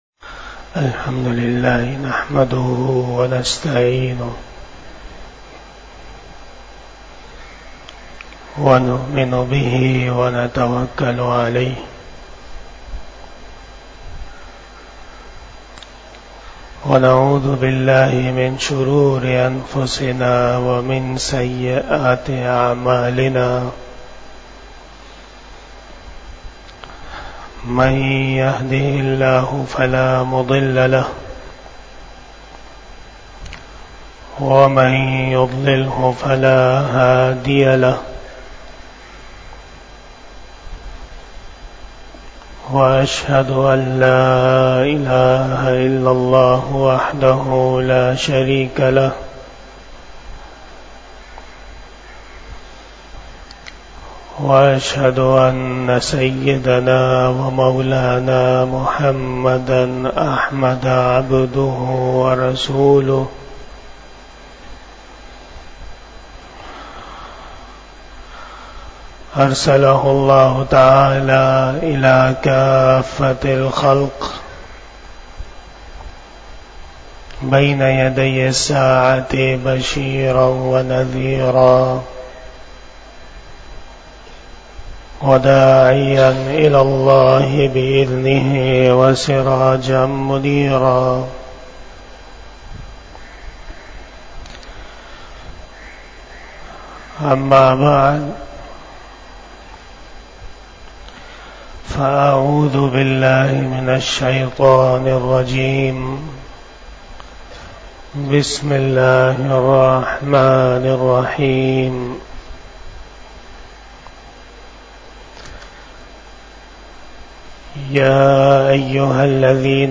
47 Bayan E Jummah 24 November 2023 (09 Jamadi Oula 1445 HJ)
12:30 PM 243 Khitab-e-Jummah 2023 --